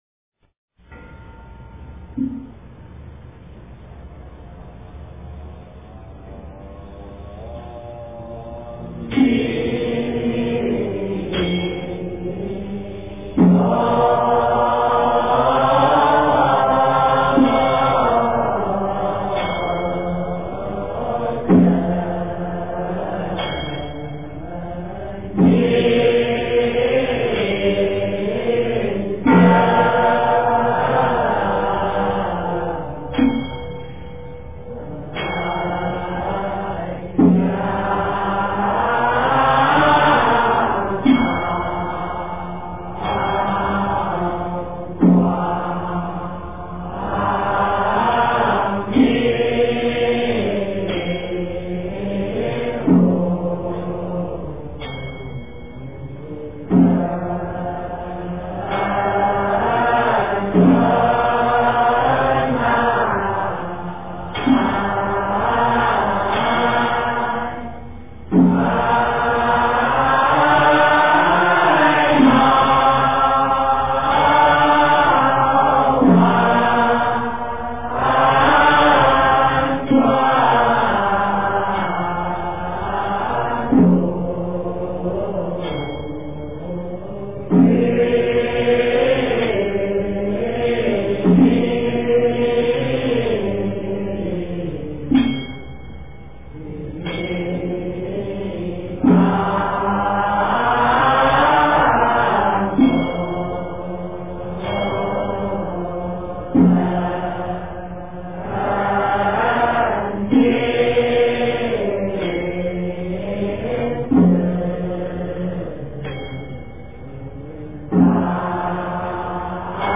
晚课-赞佛偈--僧团 经忏 晚课-赞佛偈--僧团 点我： 标签: 佛音 经忏 佛教音乐 返回列表 上一篇： 八十八佛大忏悔文-三皈依--僧团 下一篇： 三归依--僧团 相关文章 佛说阿弥陀经--圆光佛学院众法师 佛说阿弥陀经--圆光佛学院众法师...